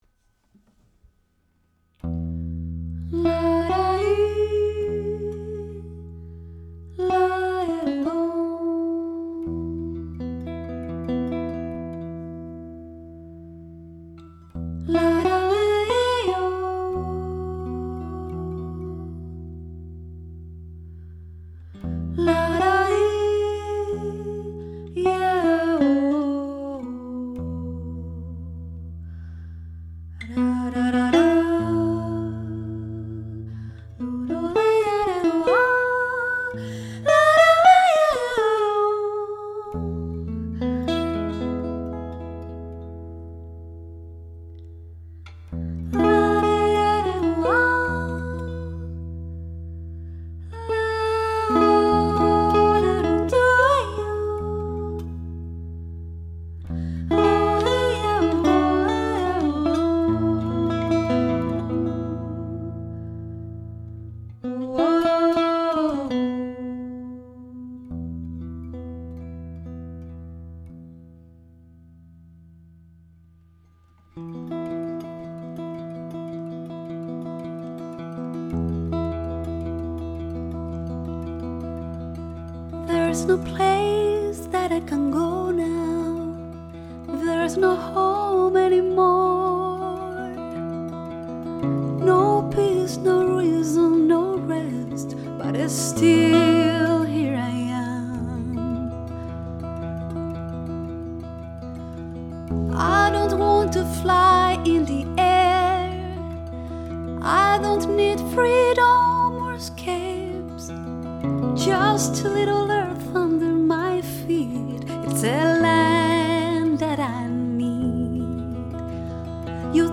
jazz
voix et guitare